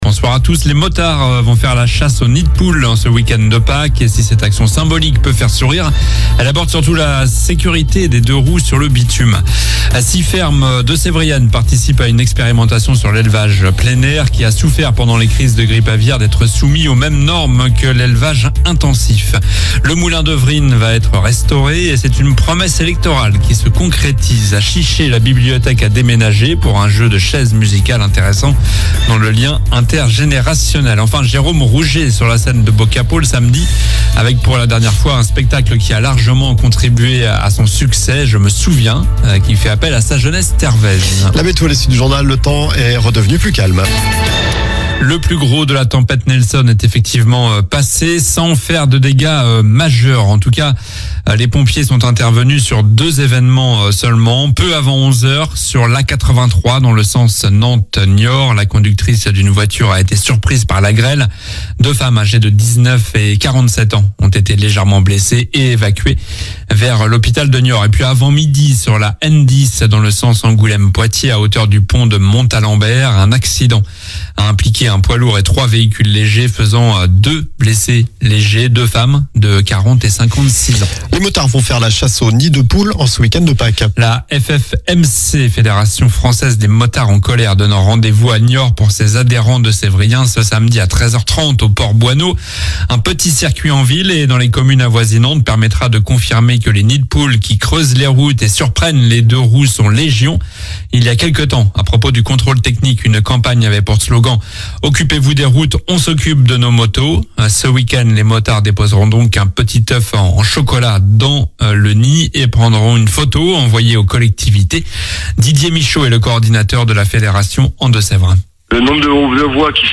Journal du jeudi 28 mars (soir)